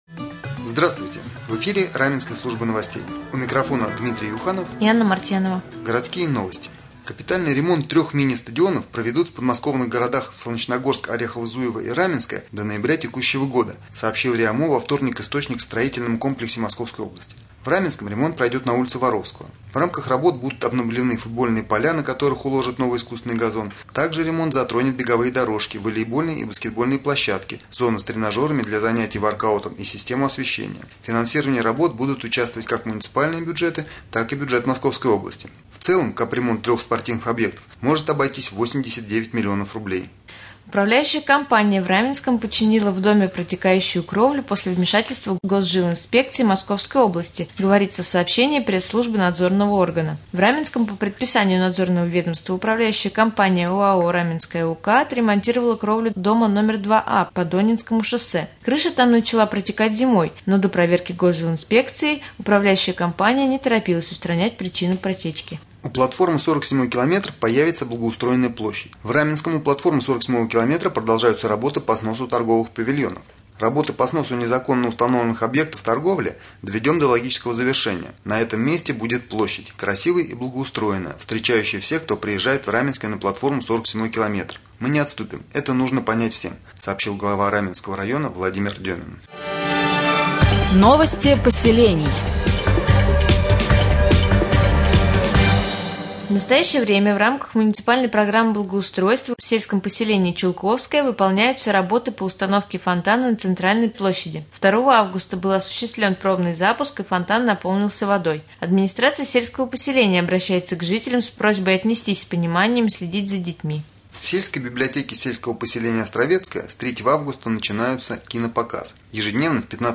1. Прямой эфир с главой Раменского района 2. Новости